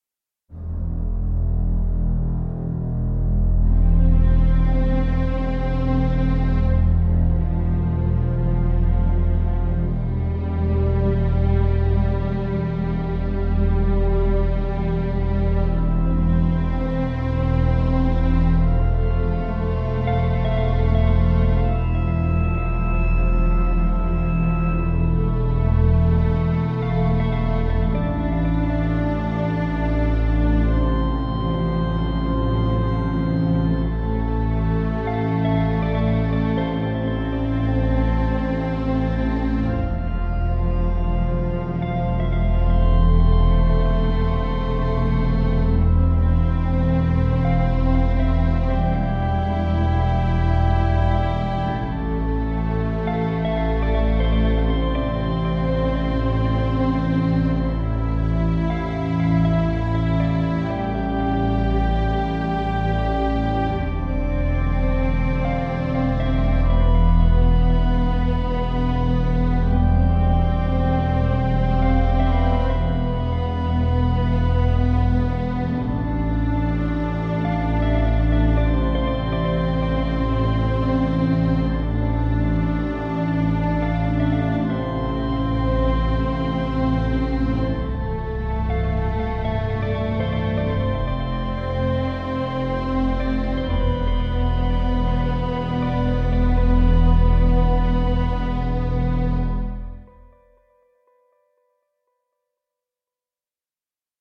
Звук тоски главного героя